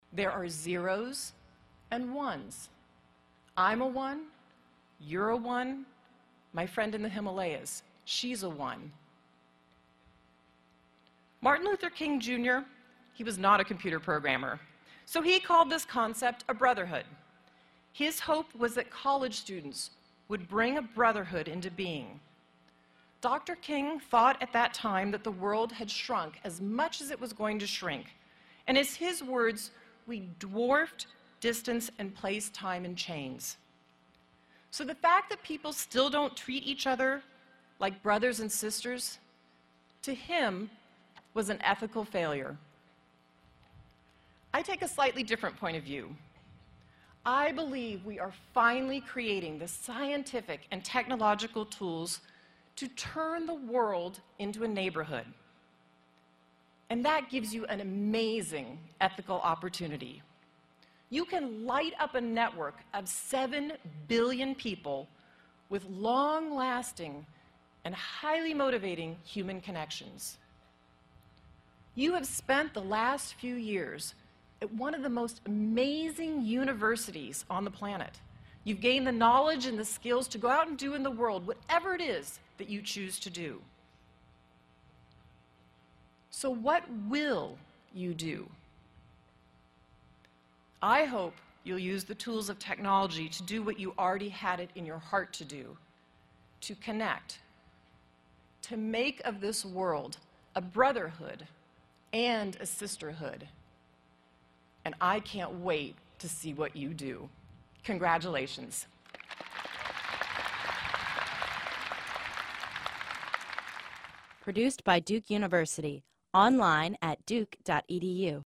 公众人物毕业演讲第351期:梅琳达2013在杜克大学(10) 听力文件下载—在线英语听力室